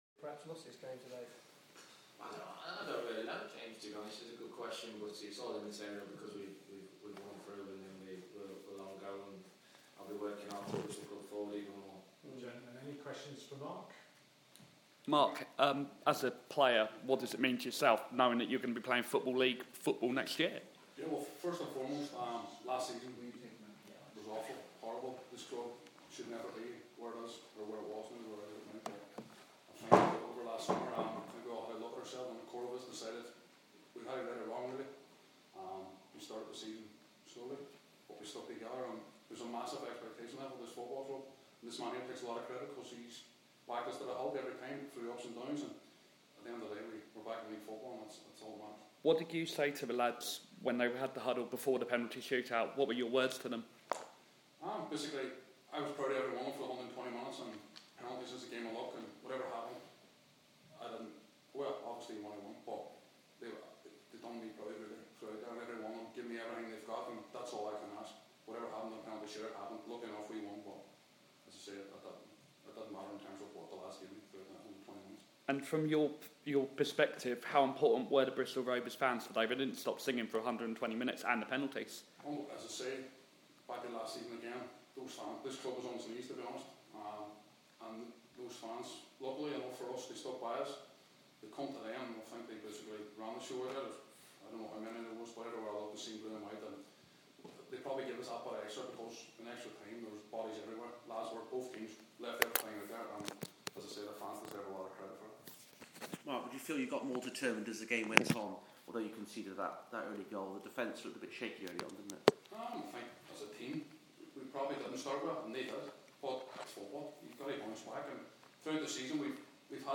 press confrence